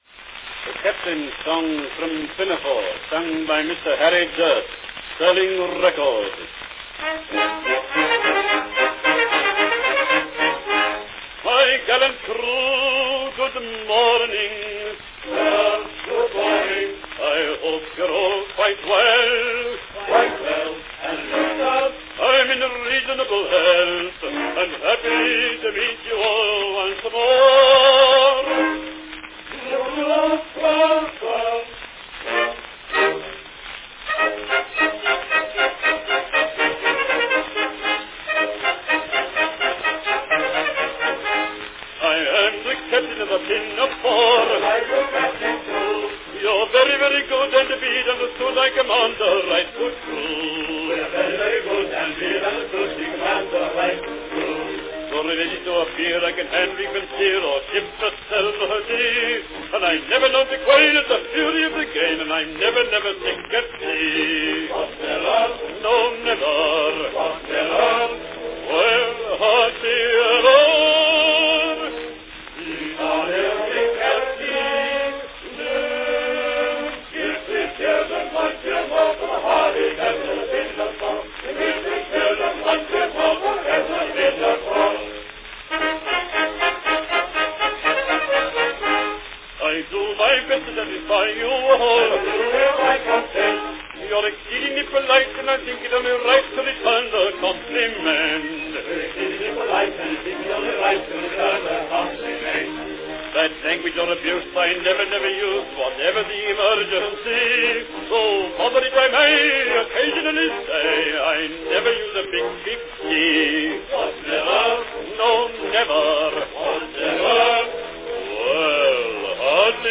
A rare recording, from 1907, of Gilbert & Sullivan's Captain's Song from H.M.S. Pinafore, produced in England by Sterling Records.
Category Baritone (with chorus & orchestra)
Performed by Harry Dearth
Announcement "The Captain's Song from Pinafore, sung by Mr. Harry Dearth, Sterling record."
Comparing a Sterling with an Edison record.
This recording of "I am the Captain of the Pinafore" is sung by the well-known British baritone Harry Dearth – a remarkable name.